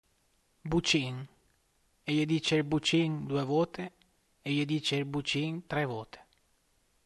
Archivio di parlato dialettale sulla base del questionario fonetico ALiR - Dati di Cravanzana e Feisoglio (CN)
I dati si basano su inchieste dialettali svolte a Cravanzana e Feisoglio (CN) con il questionario dell'ALiR: G. Tuaillon & M. Contini (1996), Atlas Linguistique Roman. Vol. I, Roma: Ist. Poligrafico e Zecca dello Stato.
Le risposte sono articolate in tre enunciati distinti: una forma di citazione + due ripetizioni all'interno di frasi cornice.